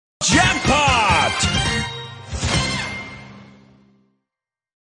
jackpot_jackpot.mp3